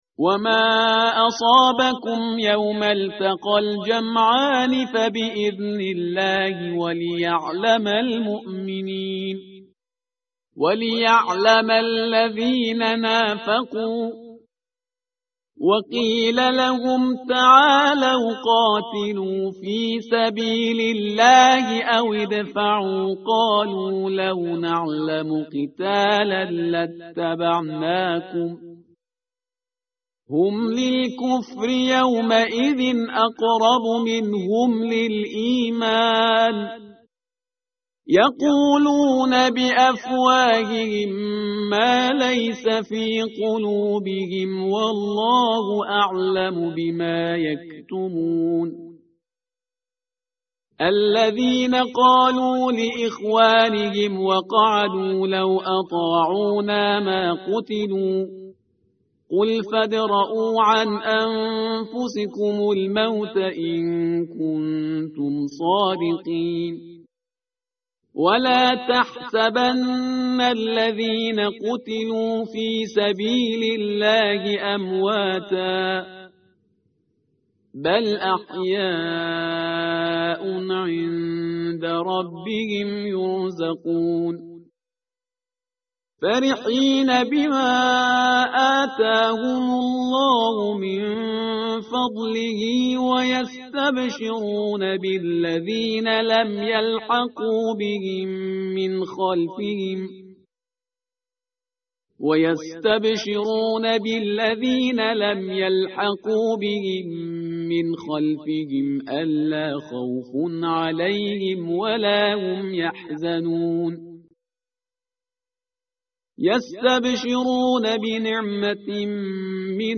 متن قرآن همراه باتلاوت قرآن و ترجمه
tartil_parhizgar_page_072.mp3